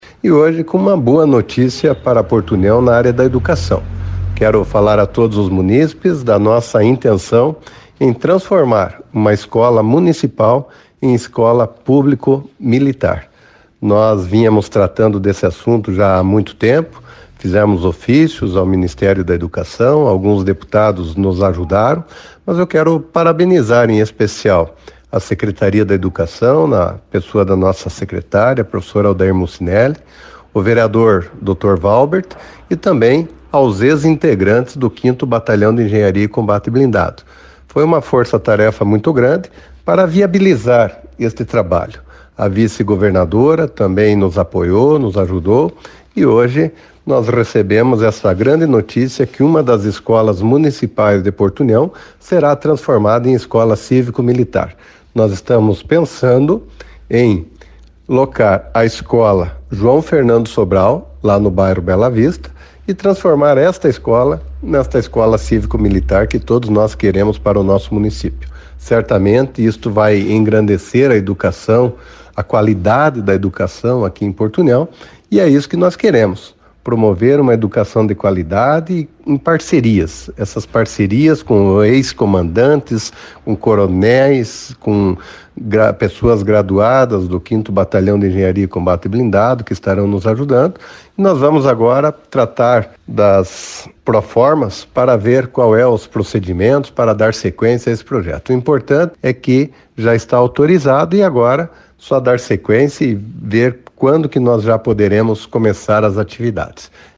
O Prefeito Eliseu Mibach também comemorou e anunciou a notícia agradecendo a todos pelo empenho. Acompanhe o áudio do Prefeito.